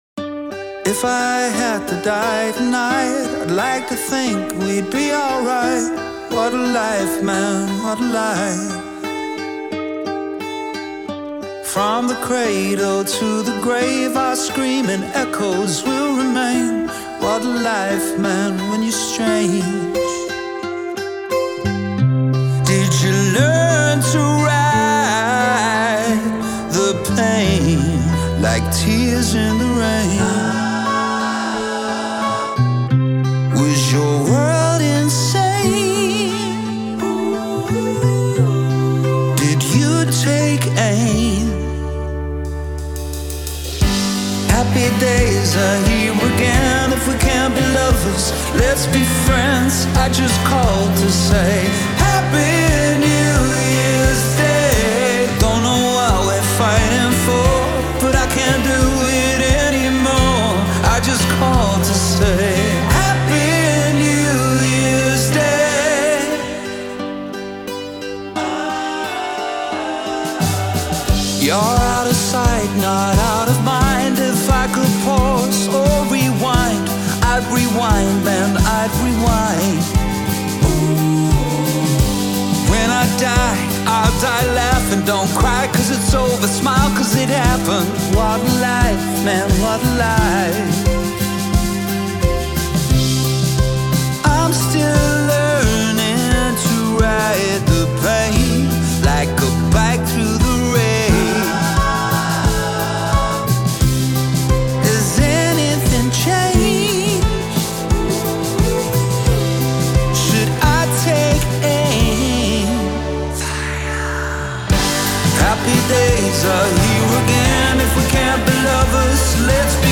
• Жанр: Корейские песни